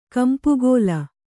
♪ kampugōla